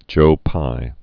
(jōpī)